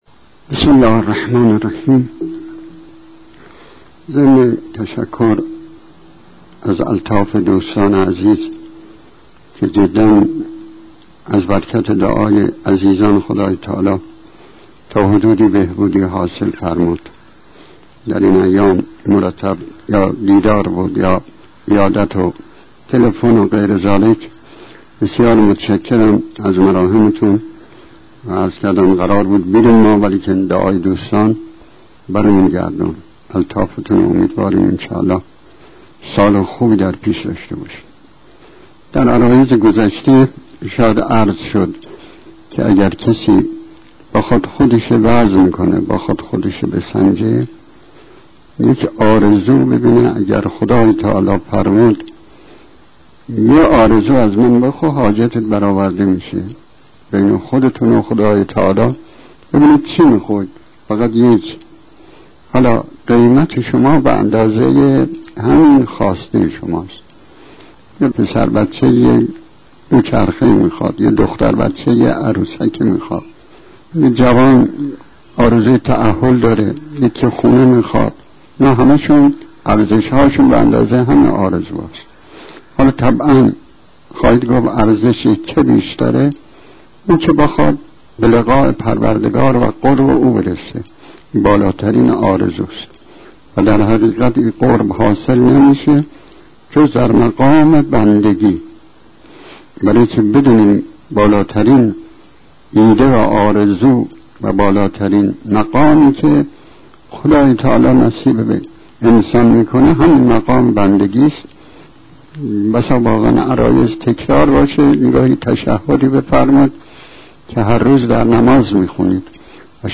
جلسات سخنرانی